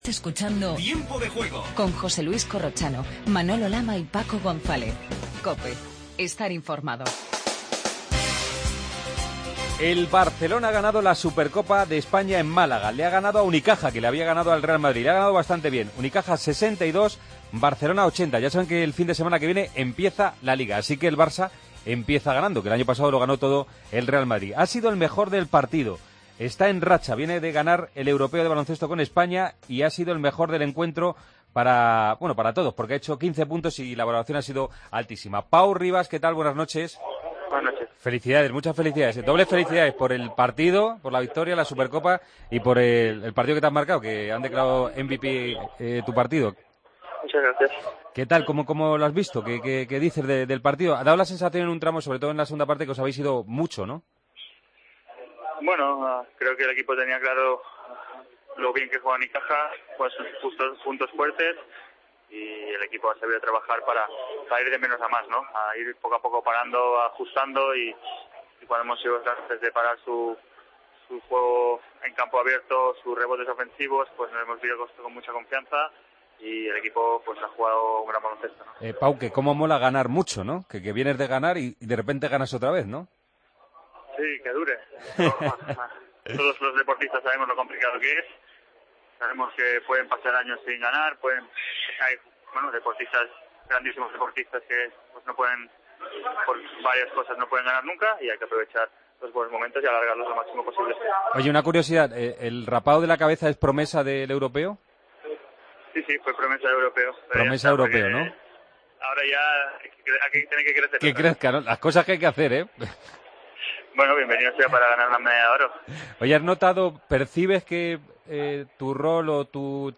Redacción digital Madrid - Publicado el 04 oct 2015, 02:45 - Actualizado 19 mar 2023, 03:17 1 min lectura Descargar Facebook Twitter Whatsapp Telegram Enviar por email Copiar enlace Hablamos con Pau Ribas, Anna Cruz y Amaya Valdemoro.